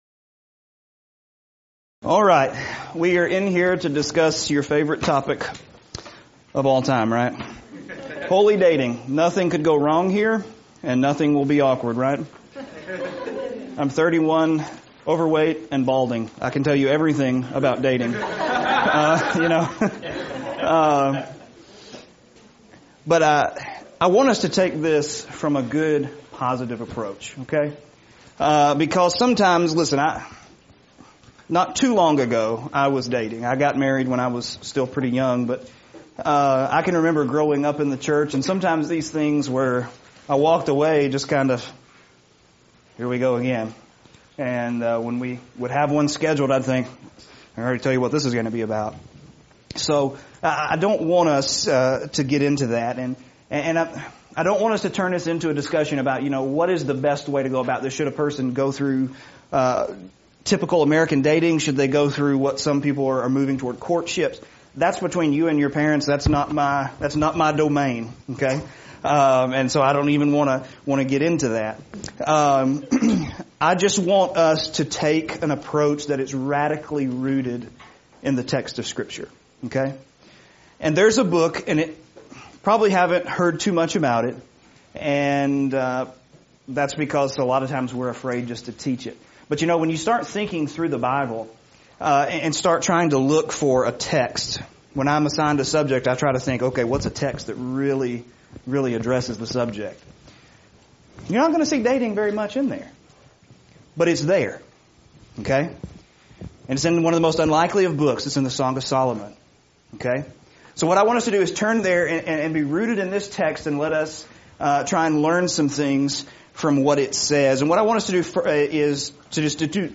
Event: 2018 Focal Point
lecture